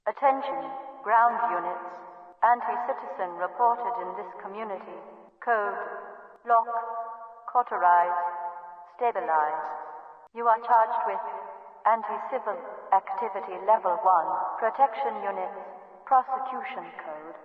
Cp Overwatch Announcement